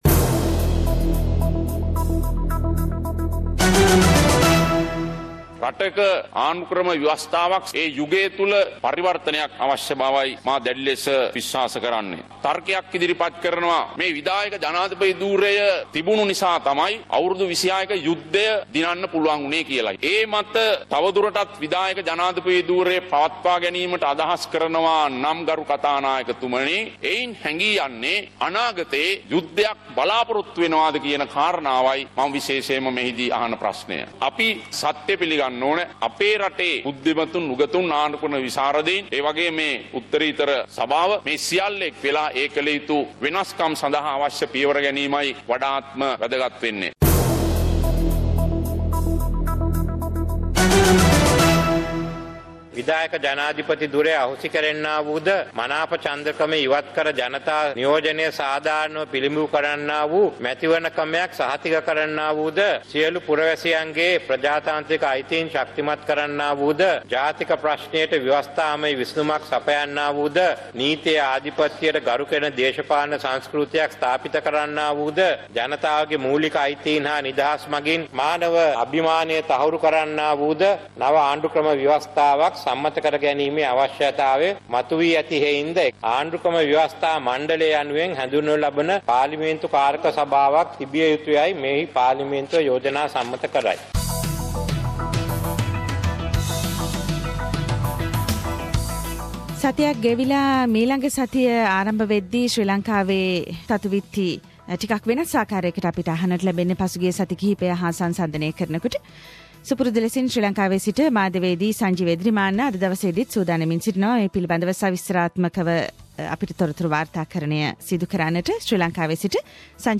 ශ්‍රී ලංකාවේ සිට වාර්තා කරයි....